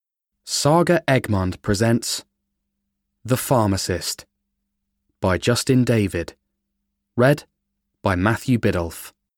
The Pharmacist (EN) audiokniha
Ukázka z knihy